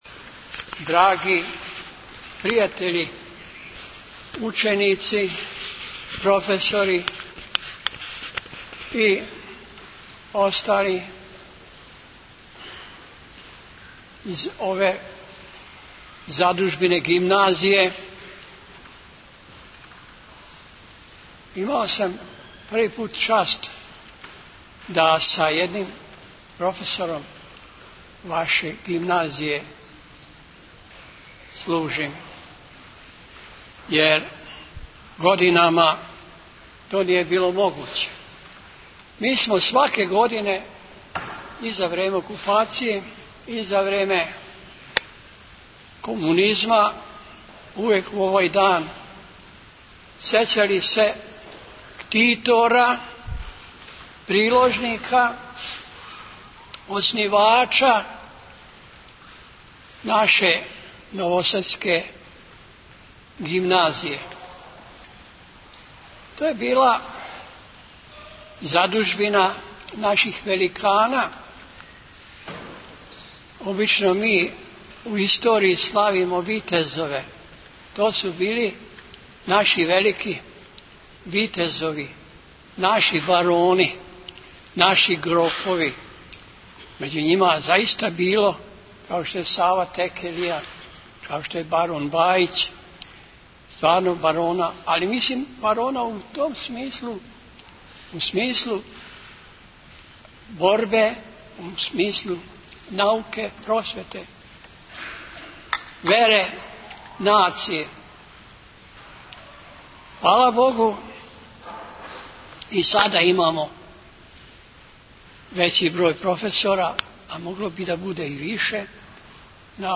пригодну беседу.